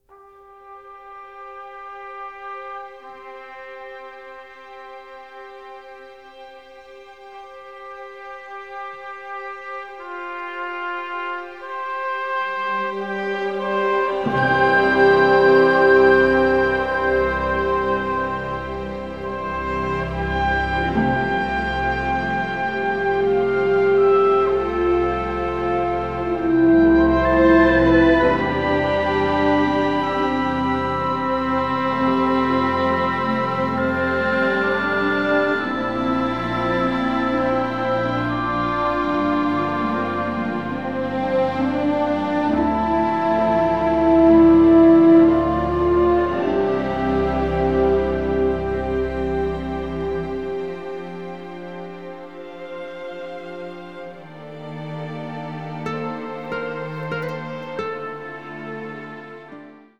emotional, symphonic Americana score